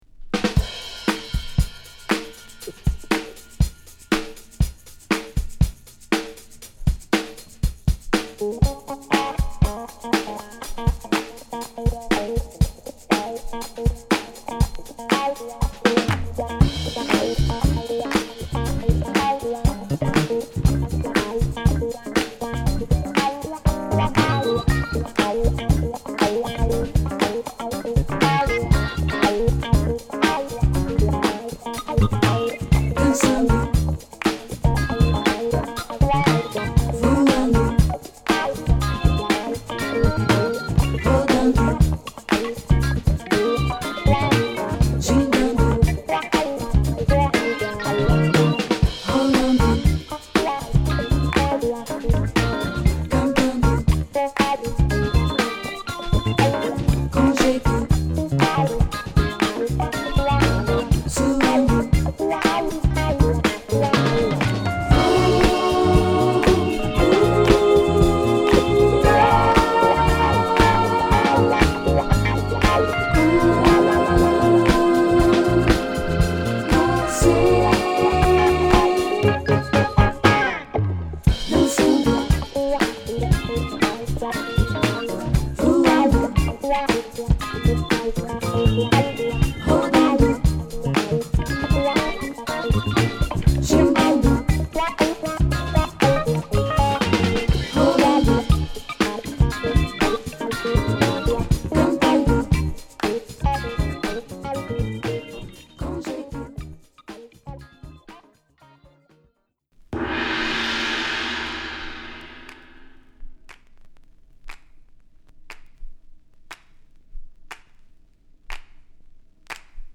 思わずステップを踏みたくなるブラジリアン・ソウル〜ファンク傑作！